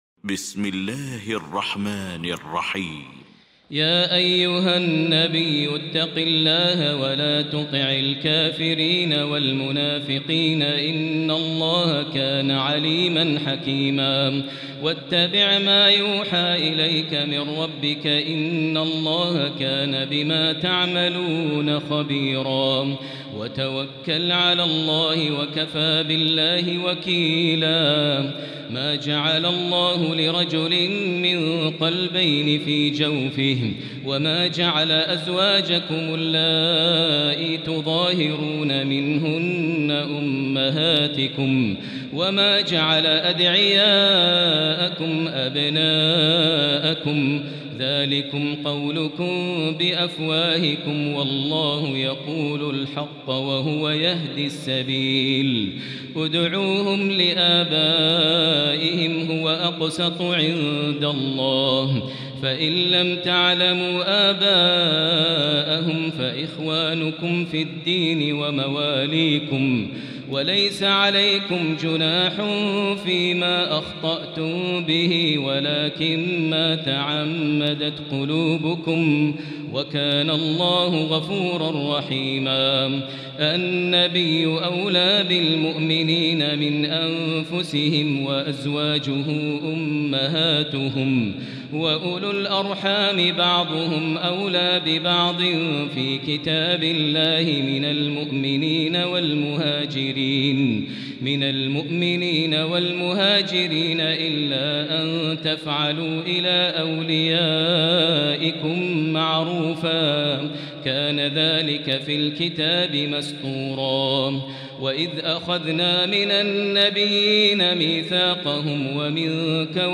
المكان: المسجد الحرام الشيخ: فضيلة الشيخ ماهر المعيقلي فضيلة الشيخ ماهر المعيقلي الأحزاب